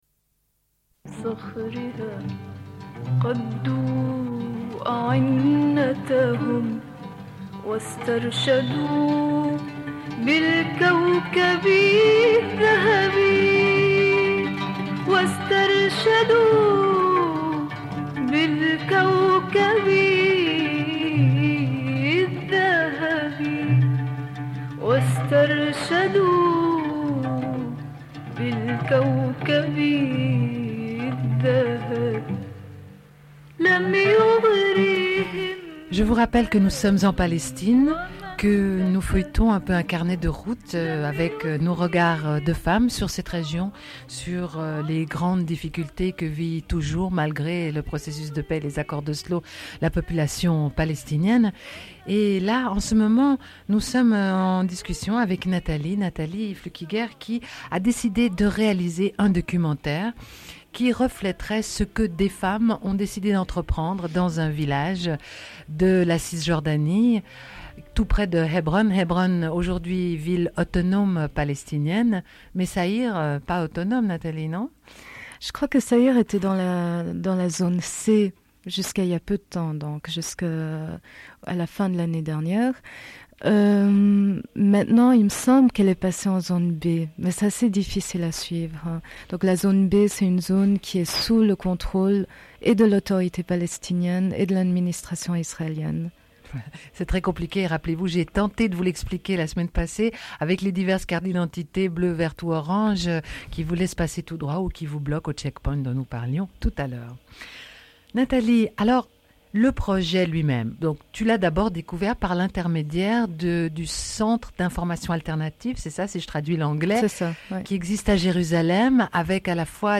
discussion en direct
Une cassette audio, face A
Radio